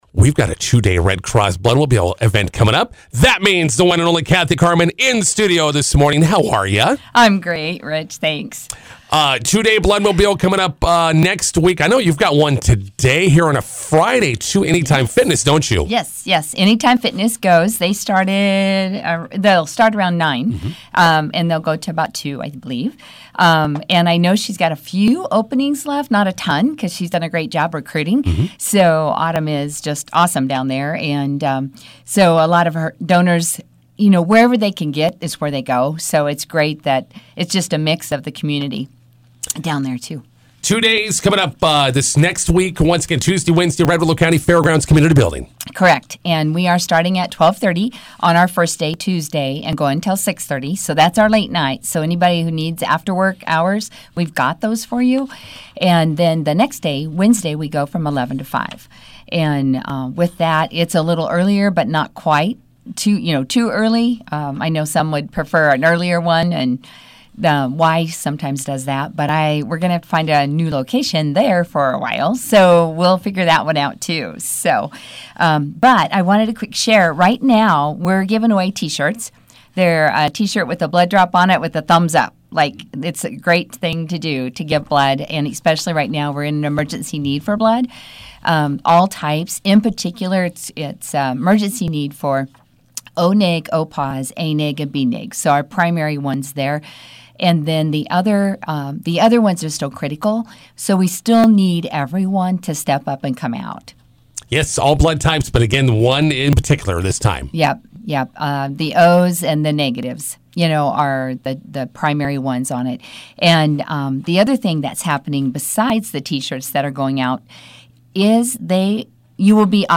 INTERVIEW: Two-day Red Cross Bloodmobile starts Tuesday in McCook.